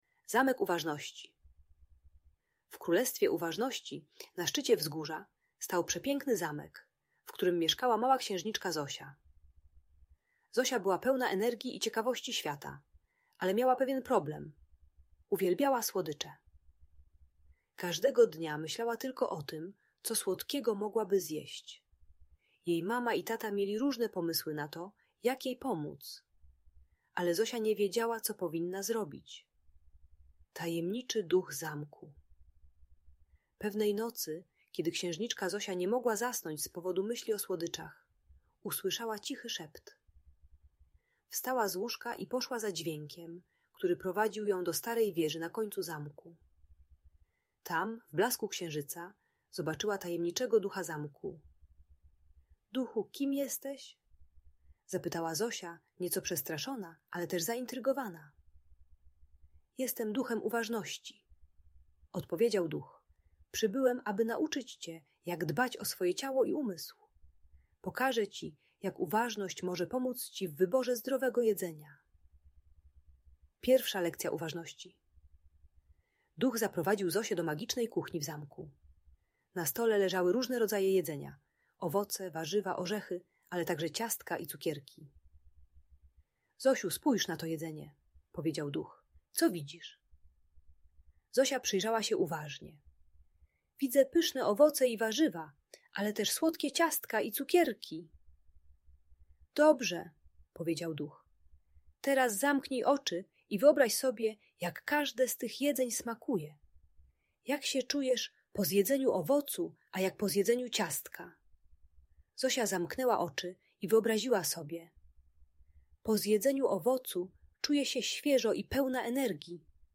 Opowieść o Zosi i Uważności - Audiobajka dla dzieci